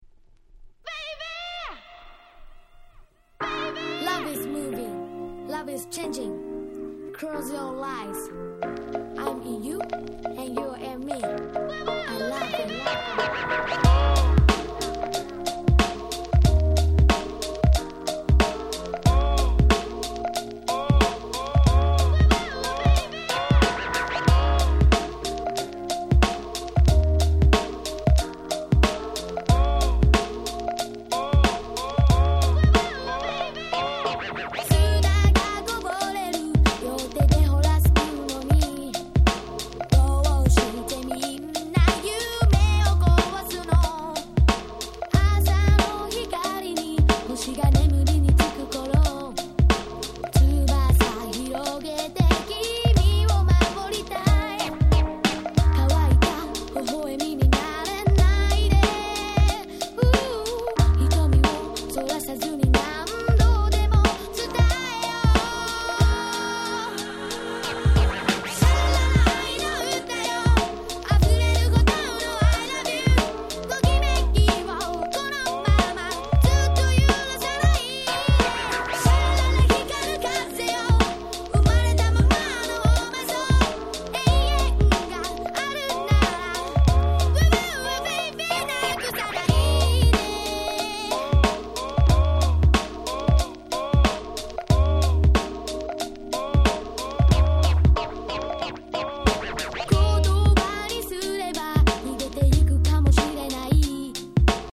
98' Big Hit J-Pop/R&B !!